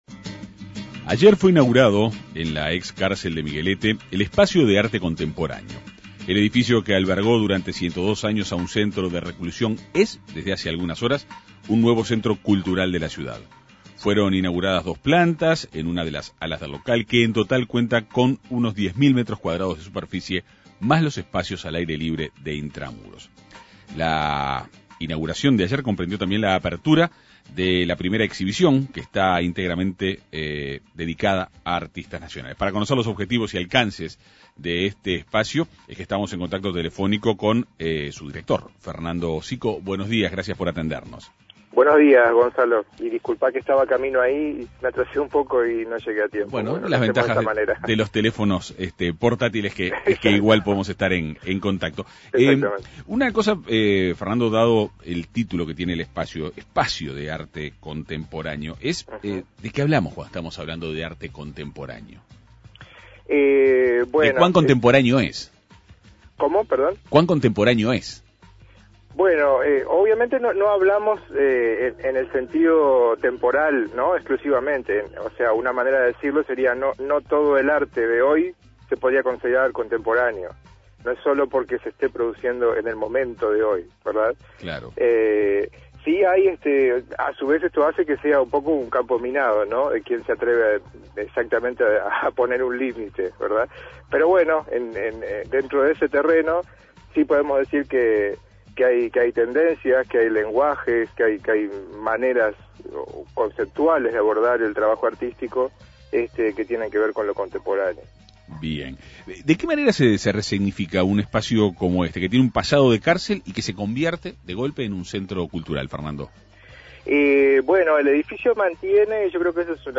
dialogó en la Segunda Mañana de En Perspectiva.